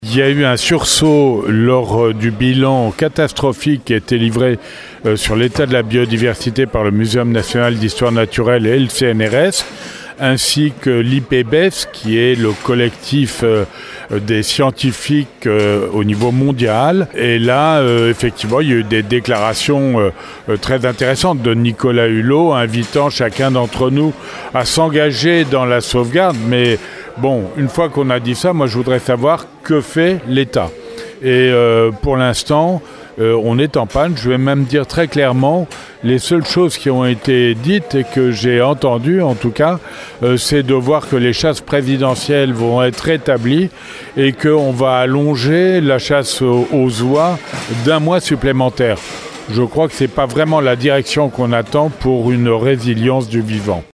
Avec un budget global de 22,4 millions d’euros qui ne couvre que 70% de leurs frais de fonctionnement. Insuffisant donc pour assurer ces missions de préservation de la biodiversité. C’est ce que nous dit Allain Bougrain-Dubourg, président de la Ligue de protection des oiseaux qui co-organise ce congrès :